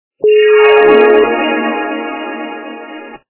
» Звуки » звуки для СМС » Звук - Для СМС
При прослушивании Звук - Для СМС качество понижено и присутствуют гудки.